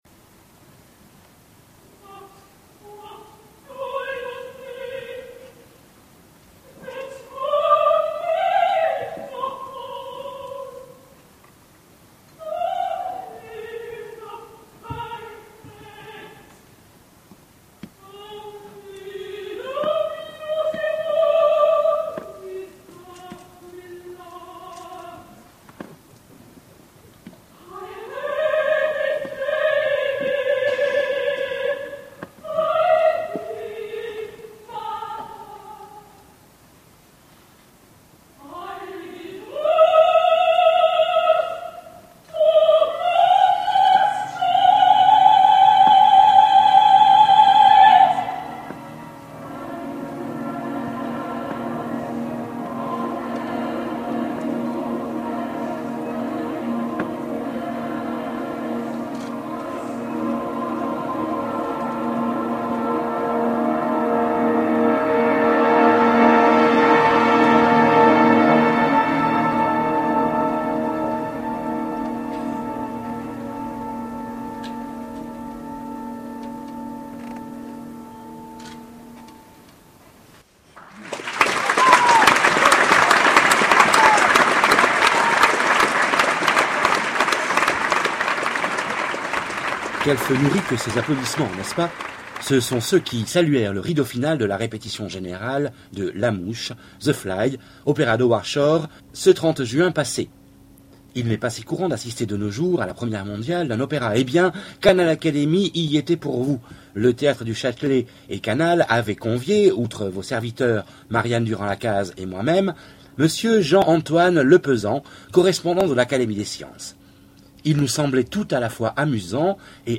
Théâtre du Châtelet, juin 2008